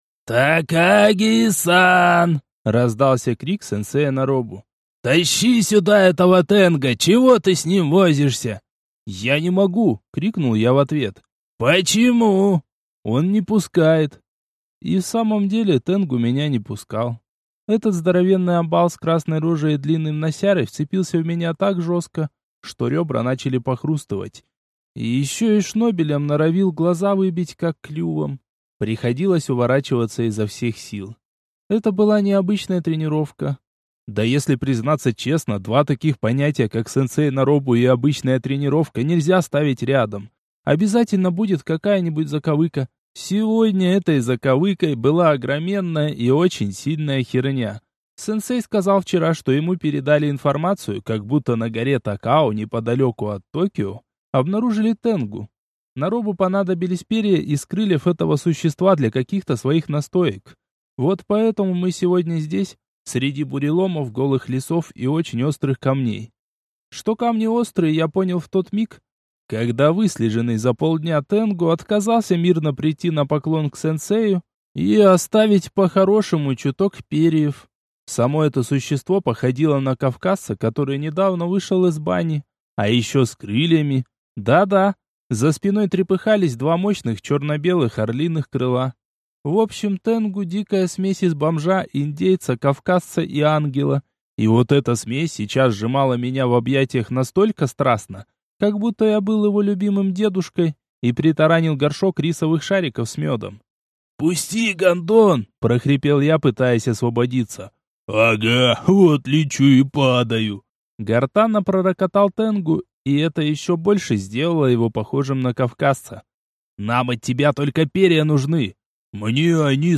Аудиокнига Якудза из другого мира 3 | Библиотека аудиокниг